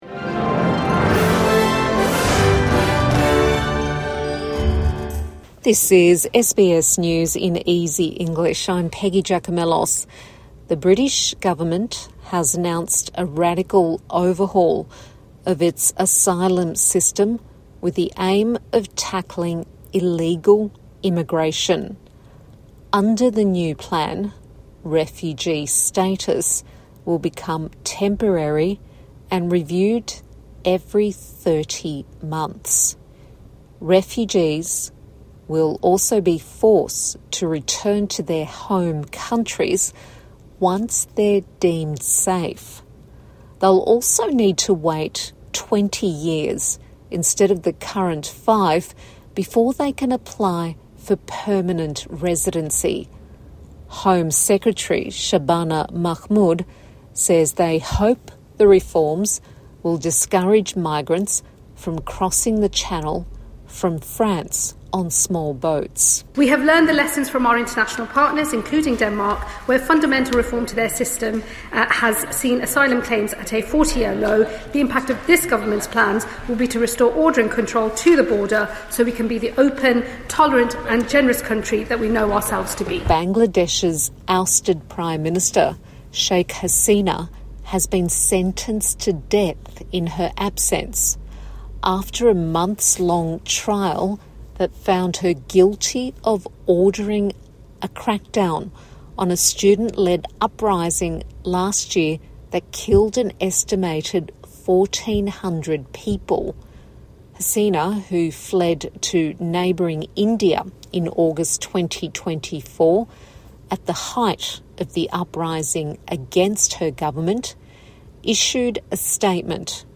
A five minute bulletin for English language learners